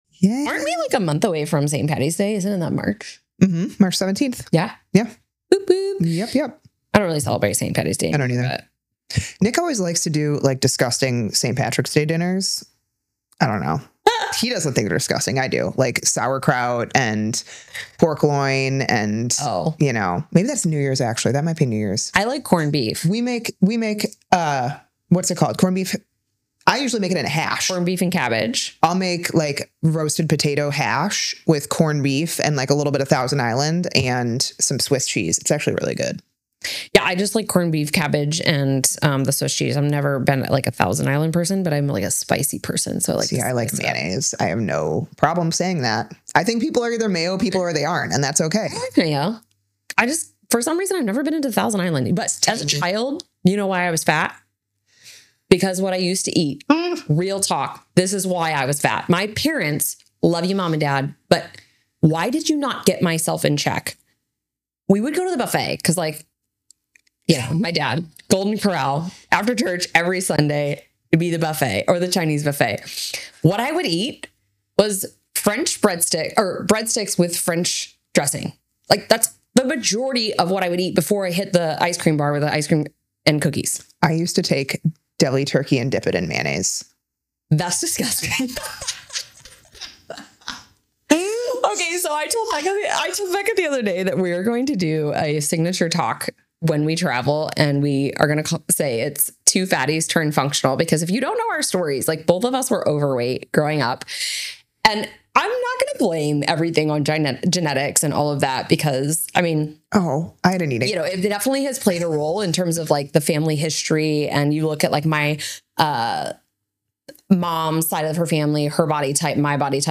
Q&A episode